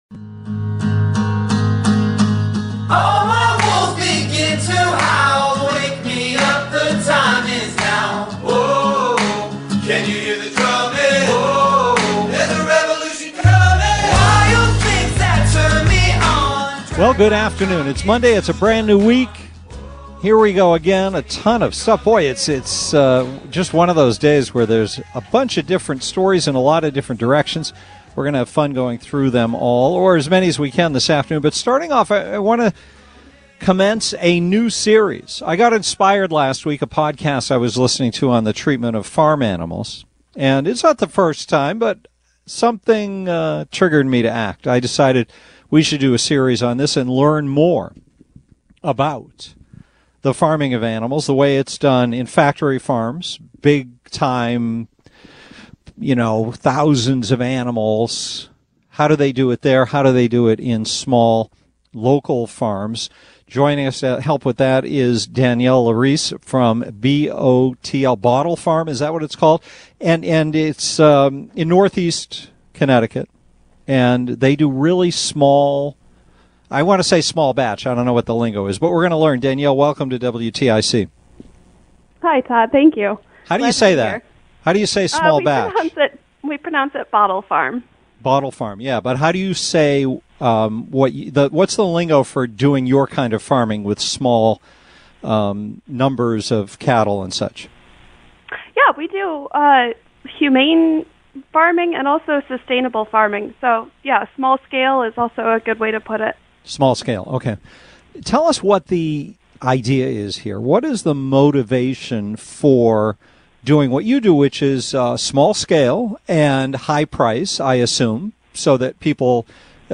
WTIC interview